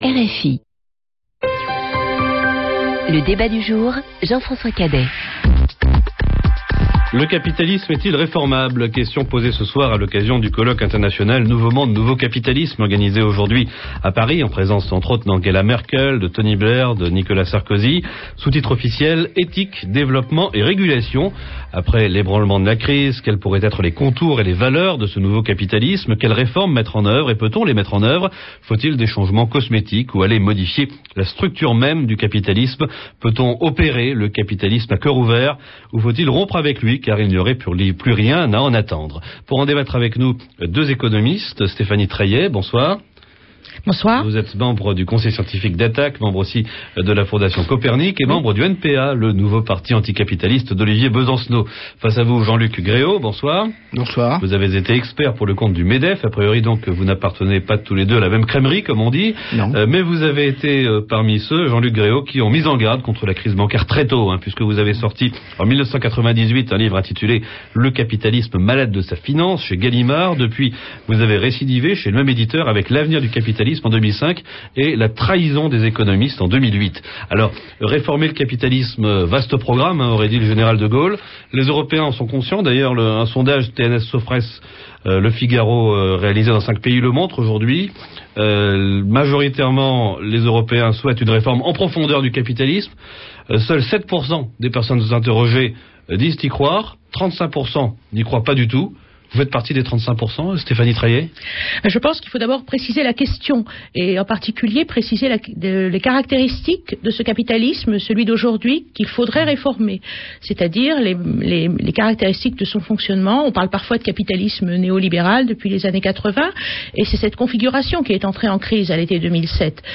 Le débat du jour, une émission de RFI 00:00 / 00:00 Le jeudi 8 janvier 2009.